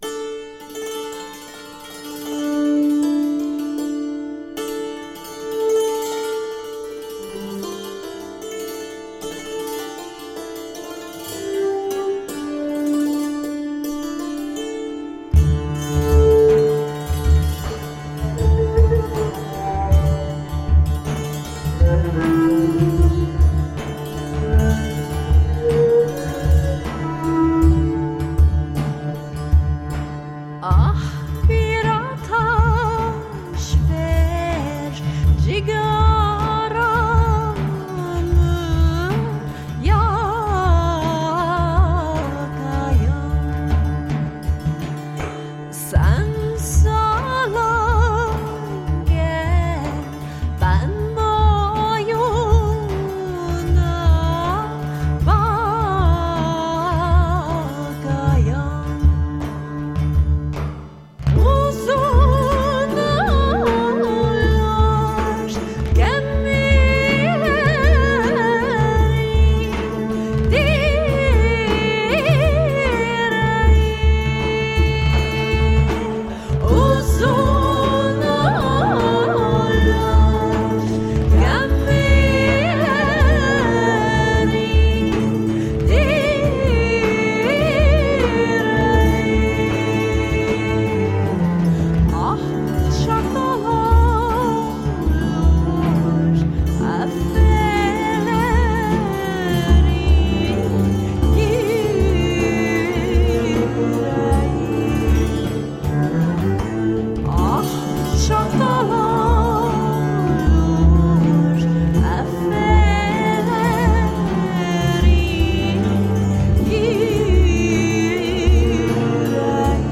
Electro-balkan / indian meets new age..
cello
Tagged as: World, Rock, Alt Rock, Indian Influenced